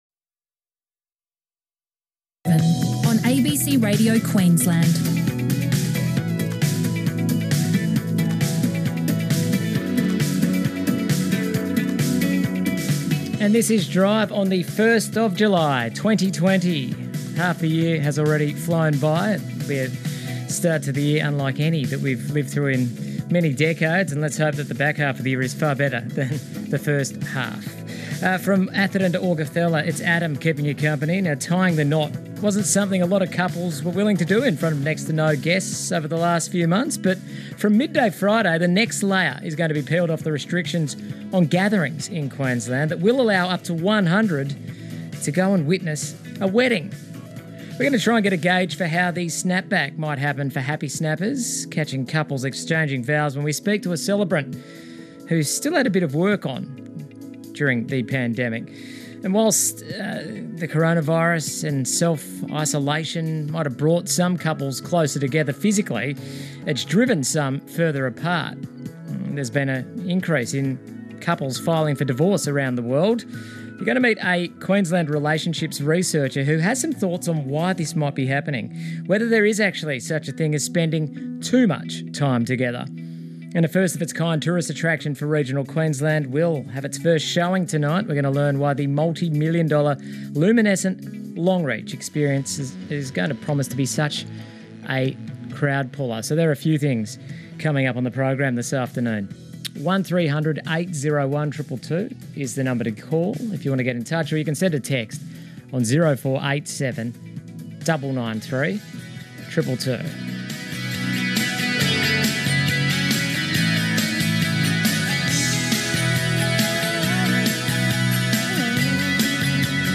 Interview
The day's news, the best music and lively conversations. Excerpt from the program broadcasted on 1 July 2020.